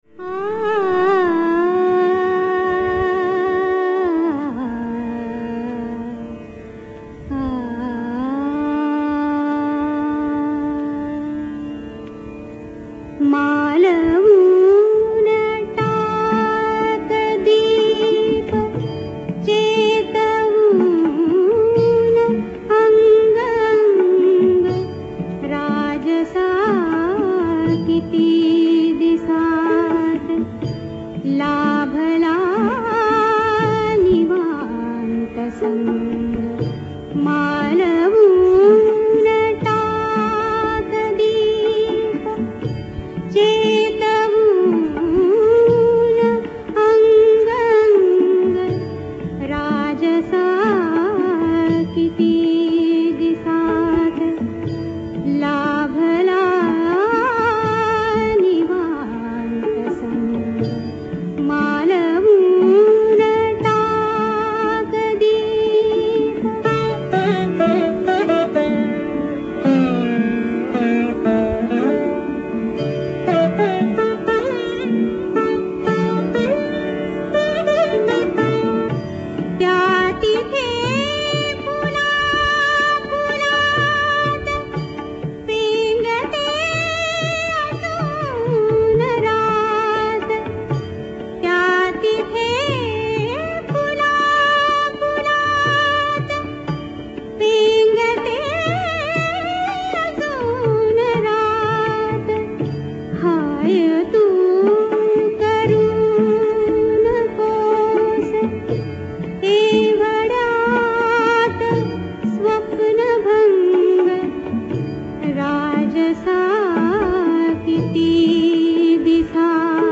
Raga Bhoopeshwari